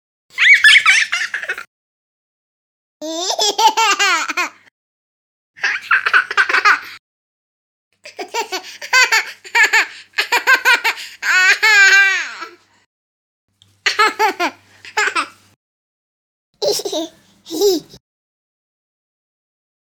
Child Ha-ha
baby bright cartoon catching child children comedy design sound effect free sound royalty free Funny